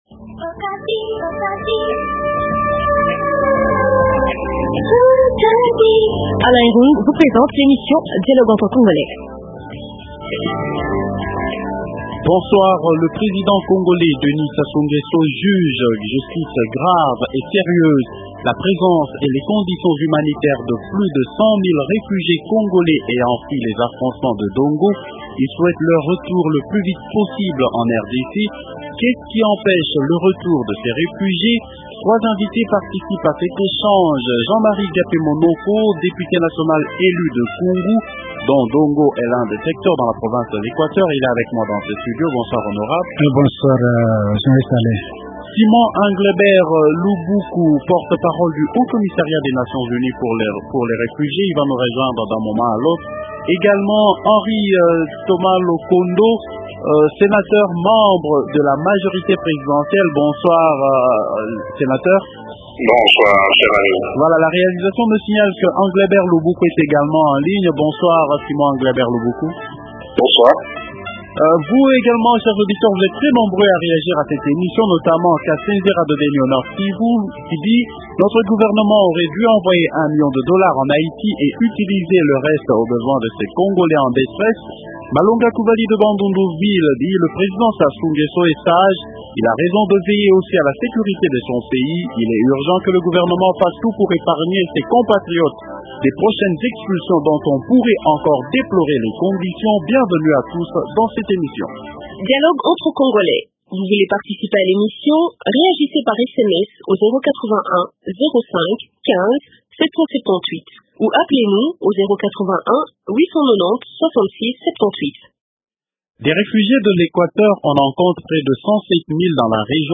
- Qu’est ce qui empêche le retour de ces réfugiés ? Invité -Jean-Marie Gapemonoko, député national élu de Kungu dont Dongo est l’un des secteurs.
-Henri Thomas Lokondo, sénateur Amprn